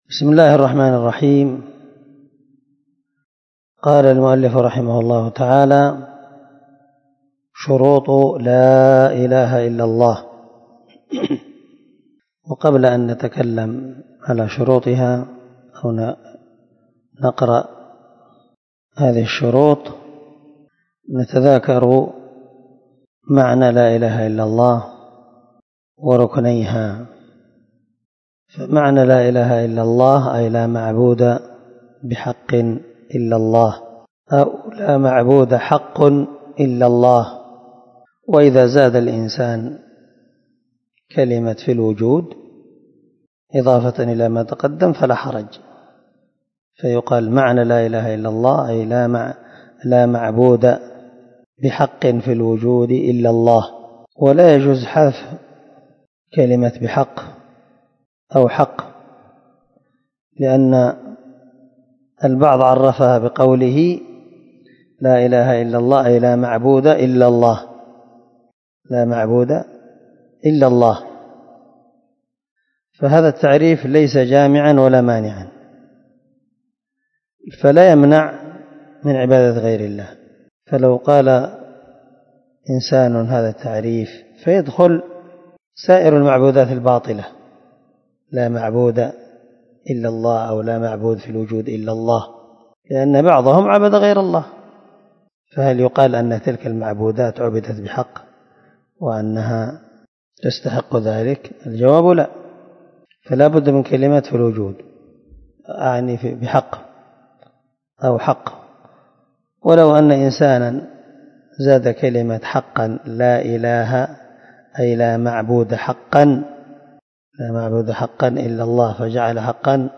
🔊 الدرس 3 معنى لا اله الا الله وركنيها
الدرس-3-معنى-لا-اله-الا-الله-وركنيها.mp3